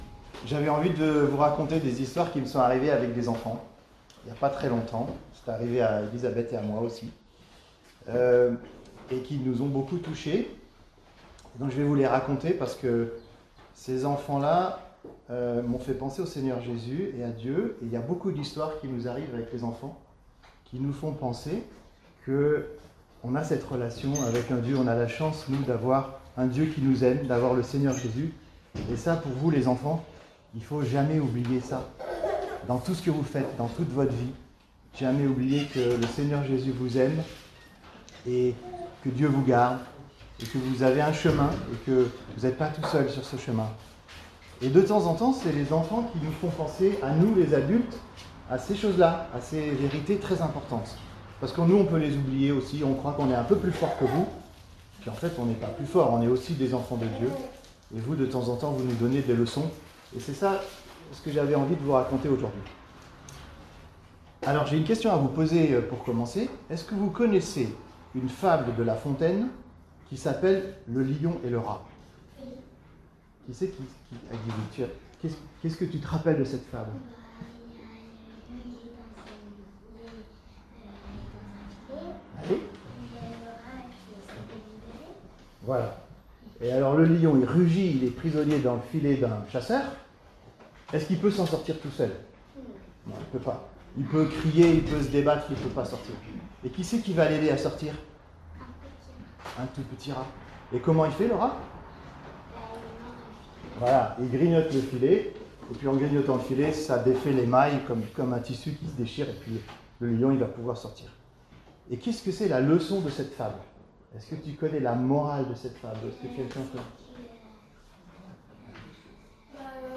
Les enfants nous apprennent aussi : message pour les enfants de l’Eglise lors de la fête de fin d’année